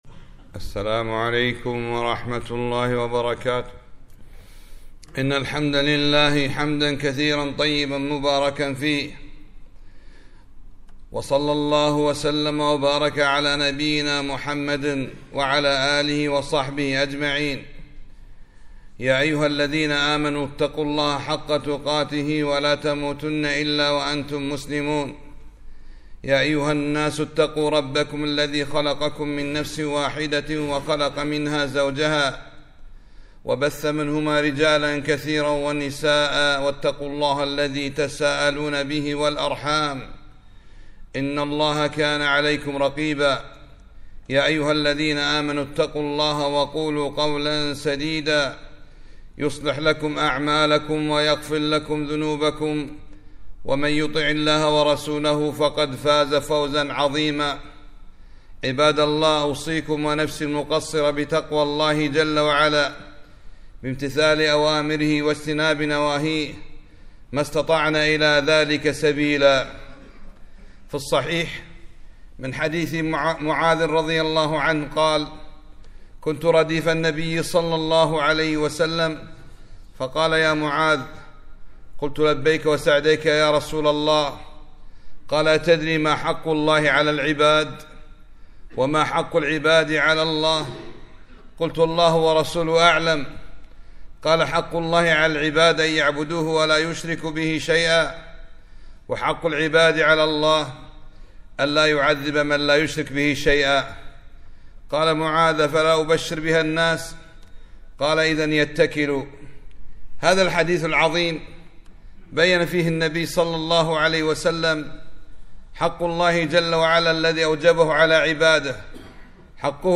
خطبة - حق الله على العبيد